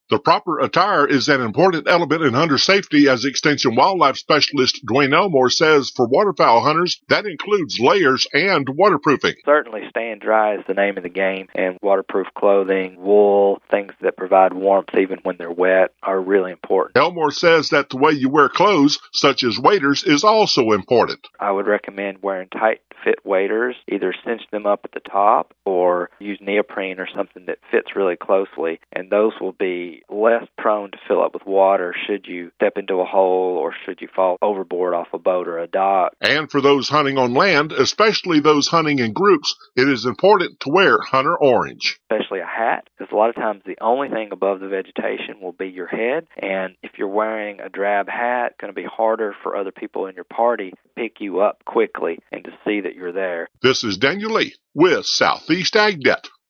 The following report has a few tips on proper clothing for all types of hunters.